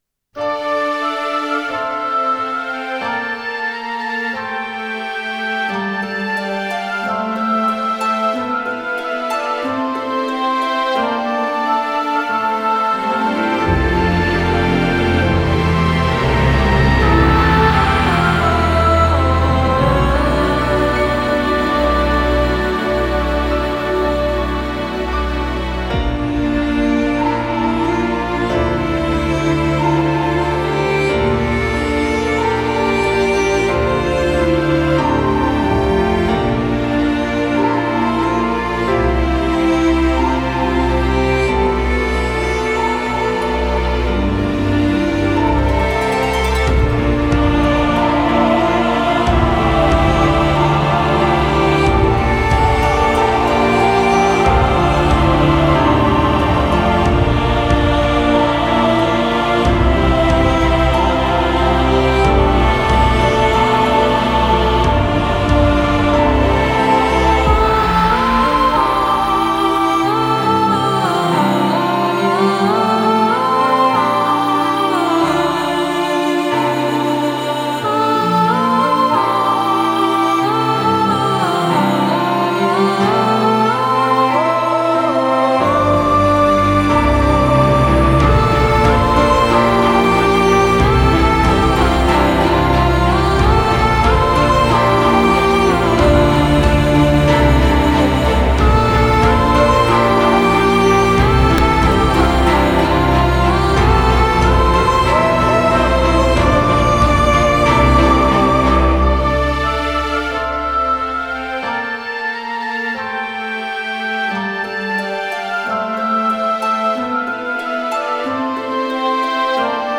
Music Remake